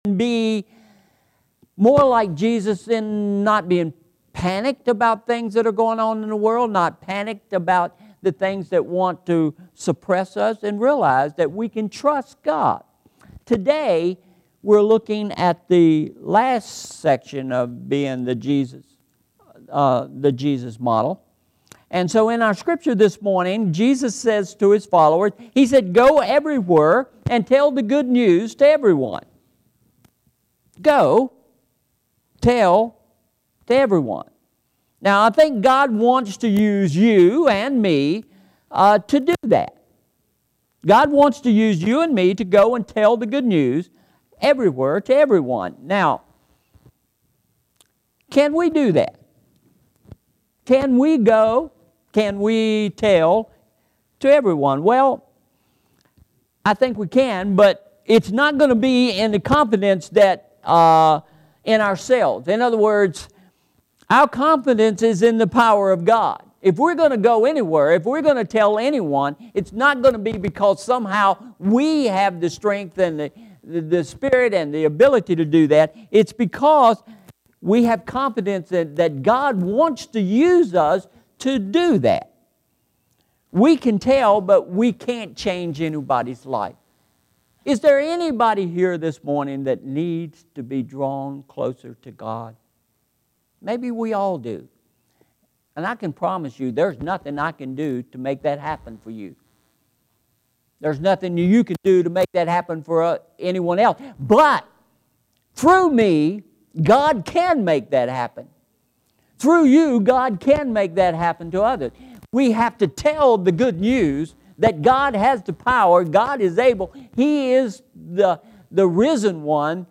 Sermon Series: The Jesus Model – Part 4